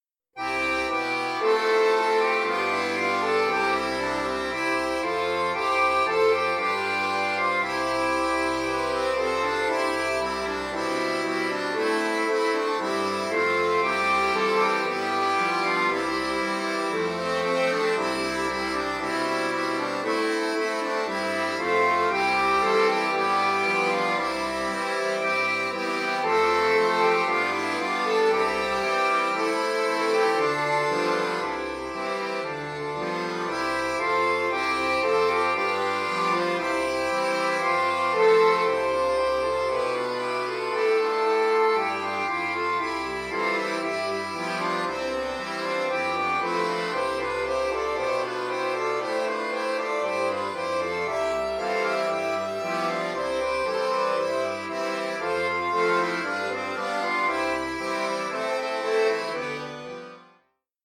Christmas Carol